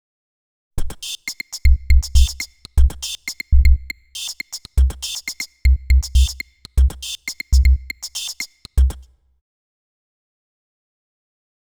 Index of /90_sSampleCDs/Inspiration_Zone/rhythmic loops
05_wavesequence_5_OS.wav